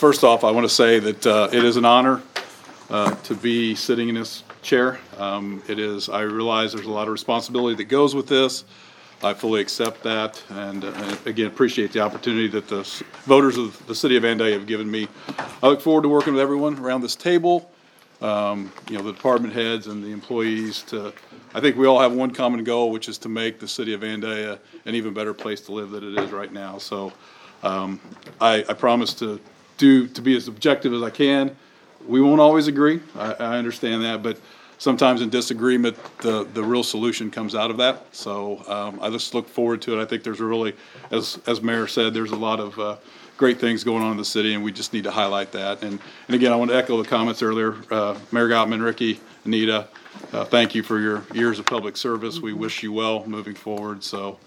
At the start of the meeting, new Mayor Knebel says he appreciates this opportunity and believes he’s up to the challenge.